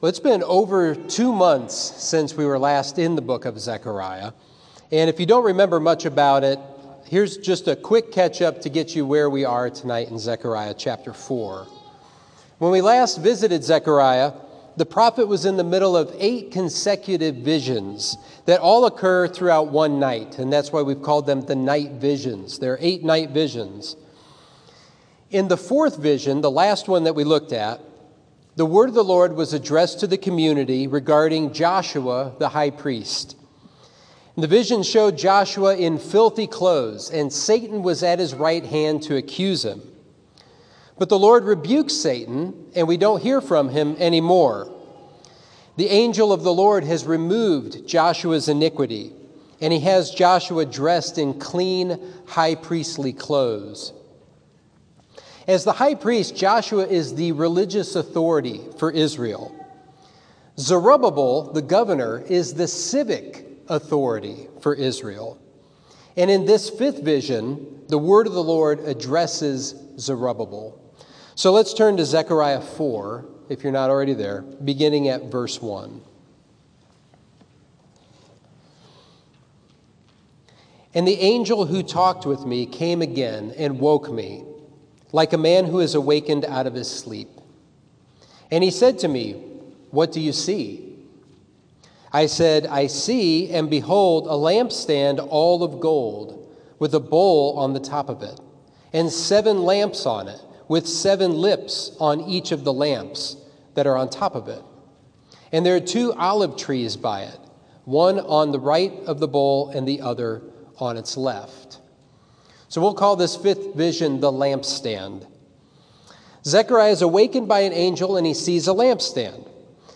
Sermon 1/16: Zechariah 4: Not by Might, Nor by Power